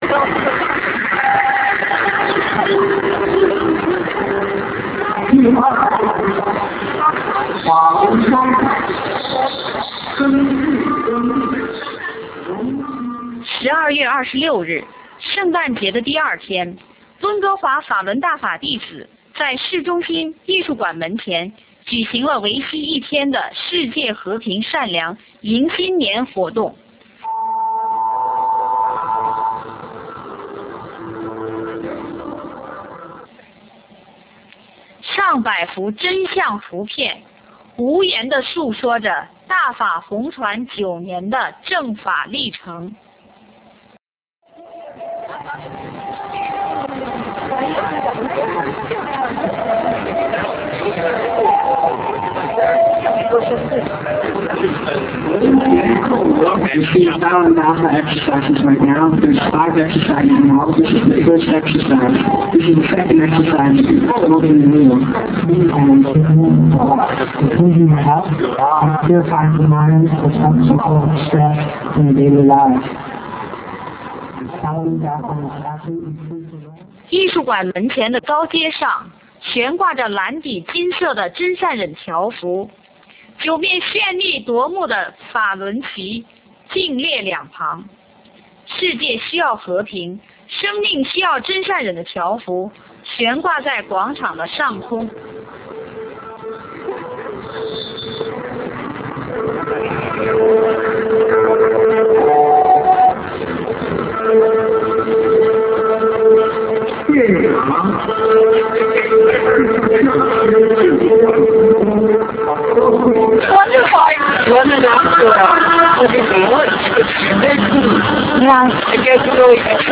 Van_news_56k.ra